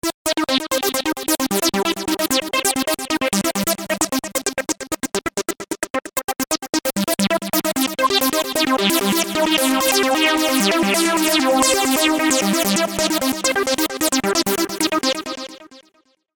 In following audio example, the gatelength of all steps is varied with a single rotary encoder - this can be achieved by pushing the ALL button: